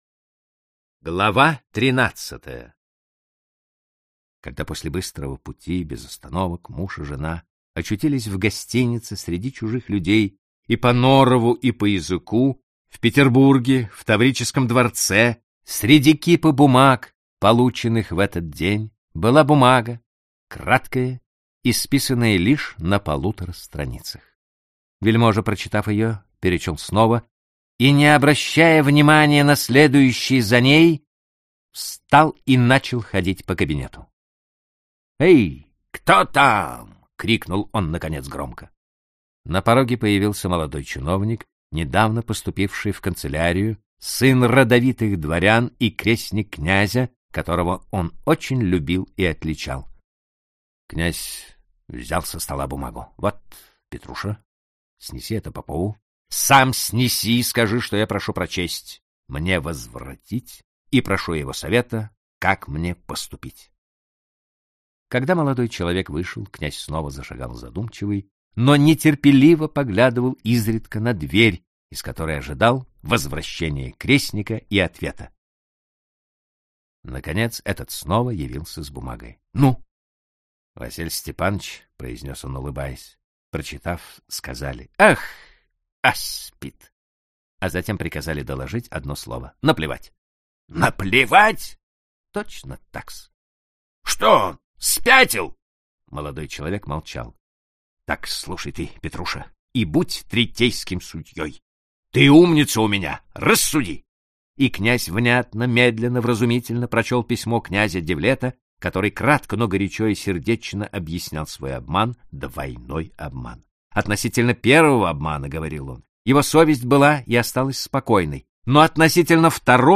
Аудиокнига Пандурочка | Библиотека аудиокниг